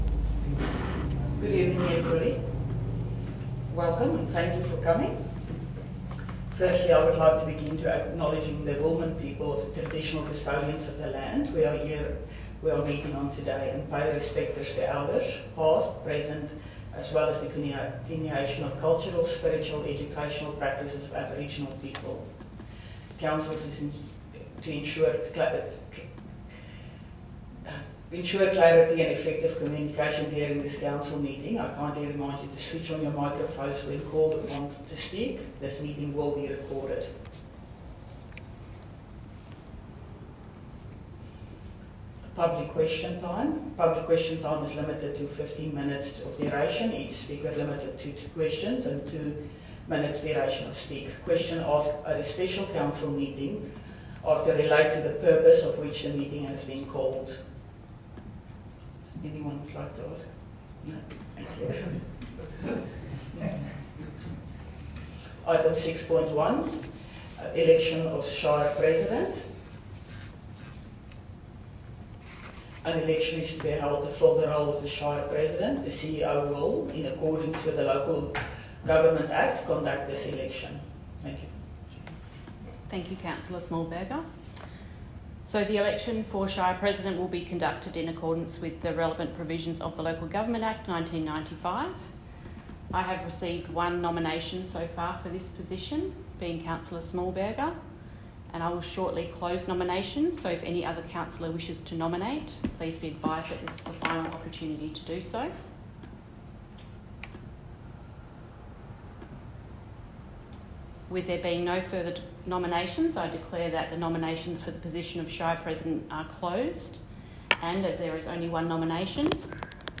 Special Council Meeting March 2025 » Shire of Boddington